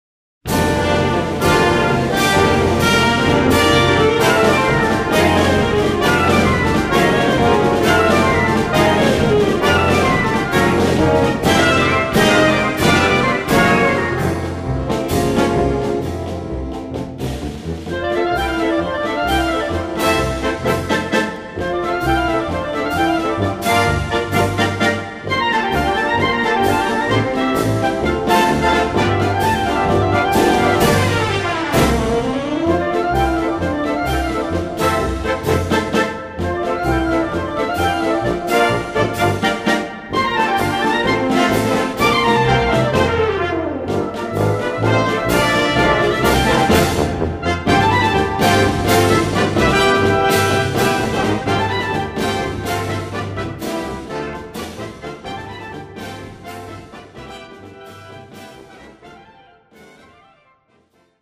輸入吹奏楽オリジナル作品